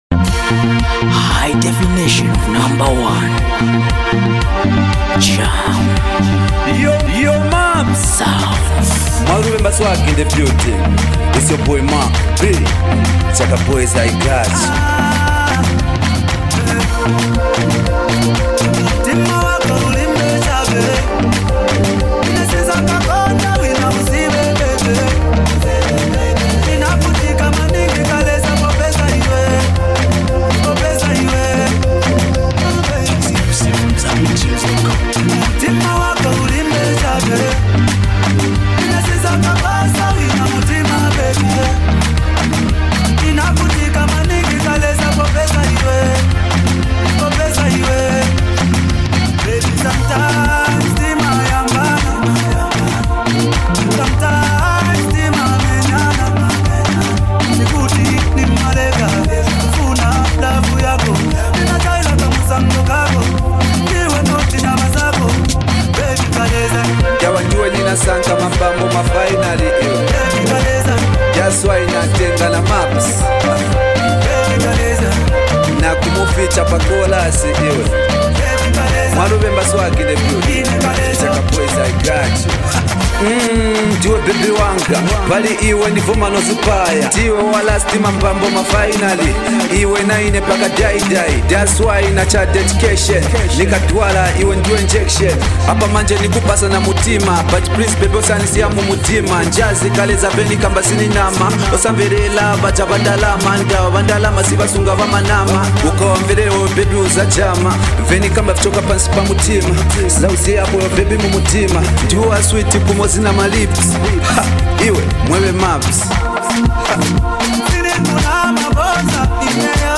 rap vocals